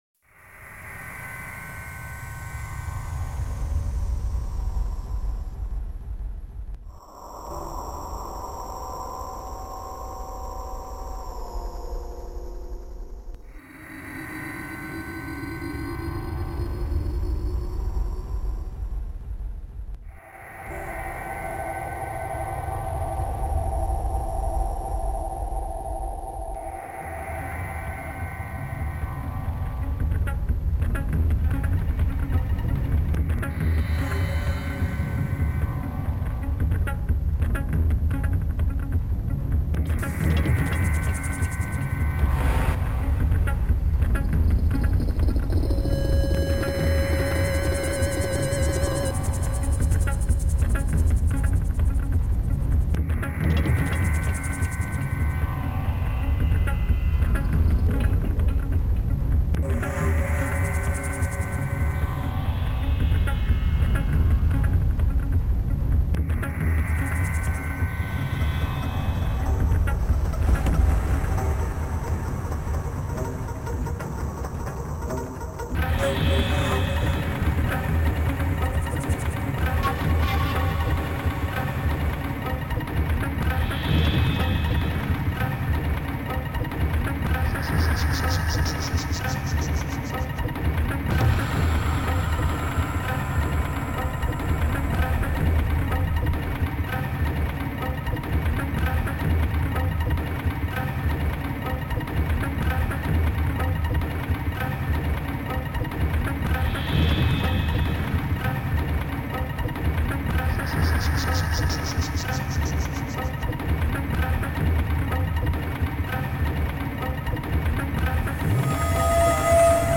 Music ( Noisetracker/Protracker )